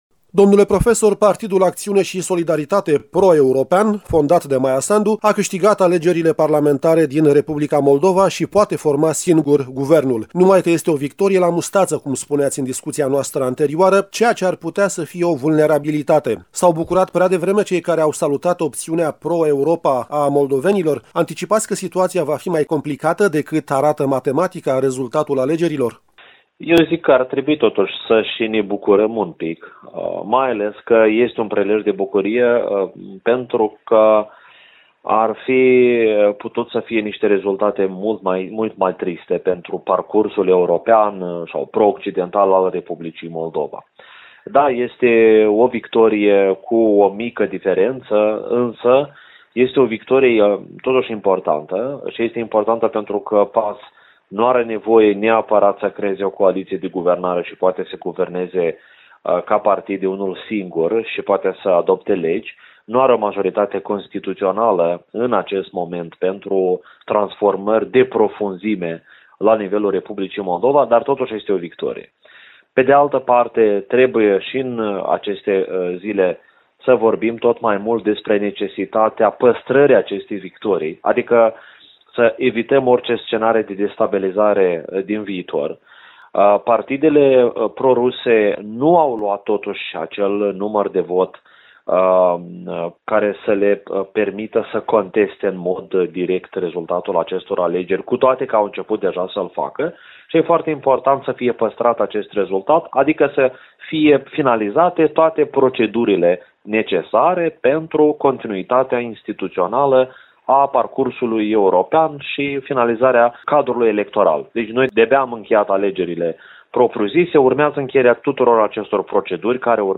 Interviu cu lect. univ.